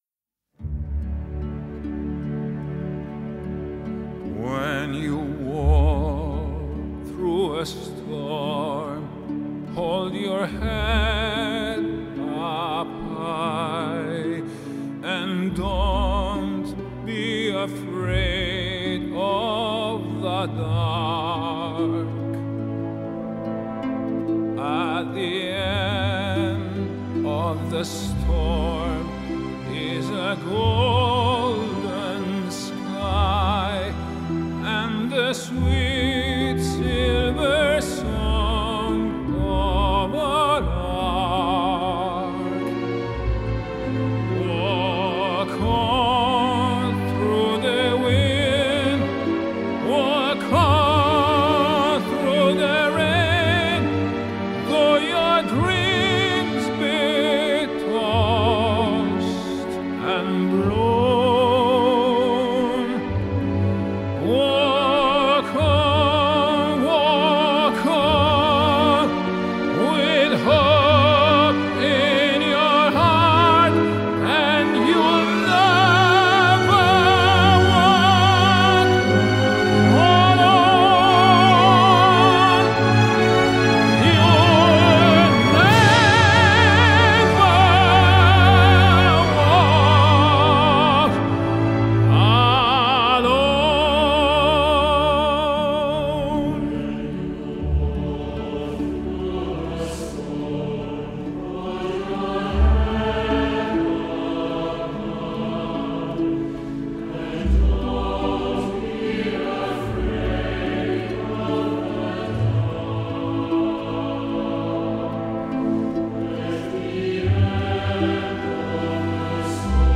Classical, Classical Crossover, Spiritual